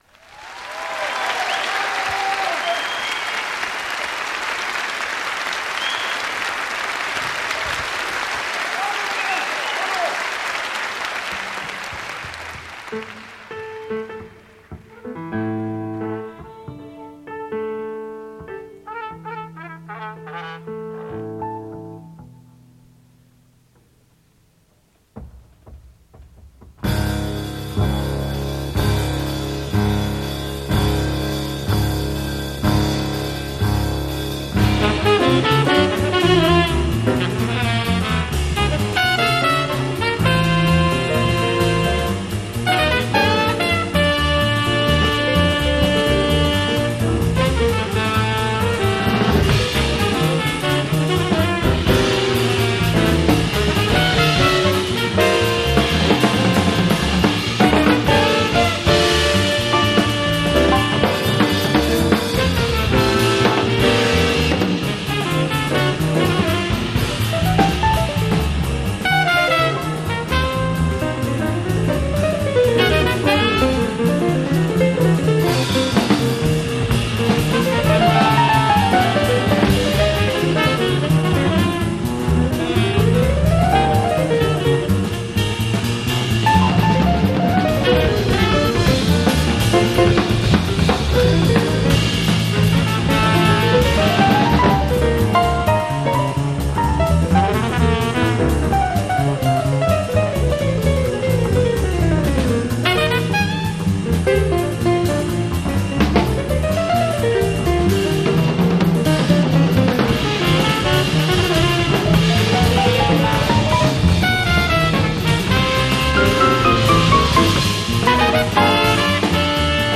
trumpet
tenor and soprano saxophone
bass
drums